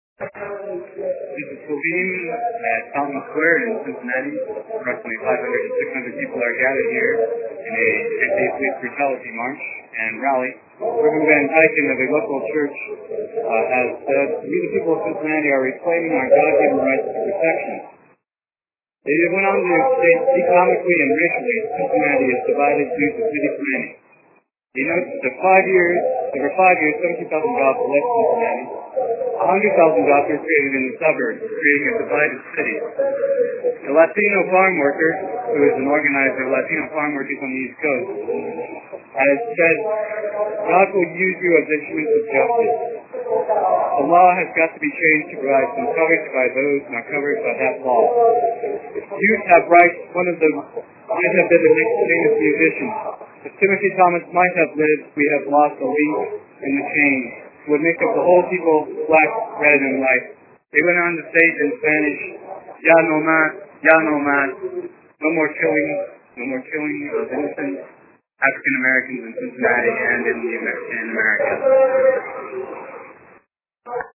An SF-IMC reporter covers speeches and viewpoints from the Cincinnati police brutality protests on June 2, 2001. (1:14)